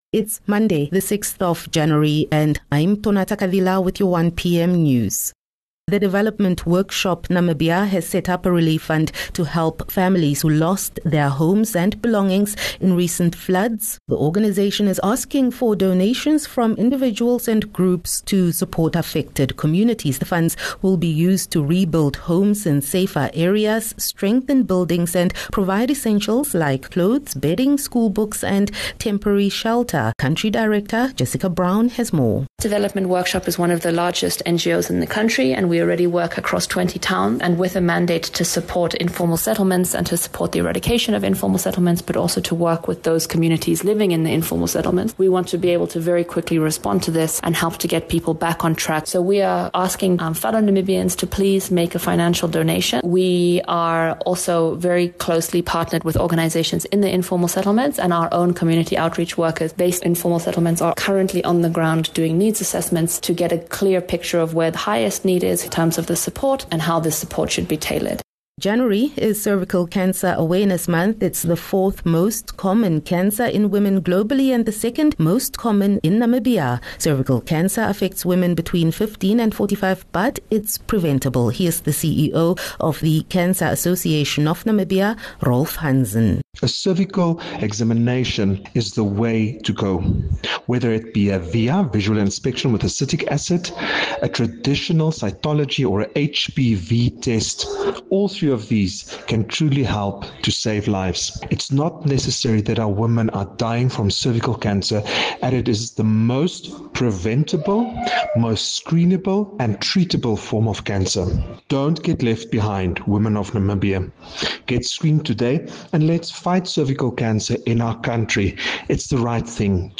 Daily bulletins from Namibia's award winning news team.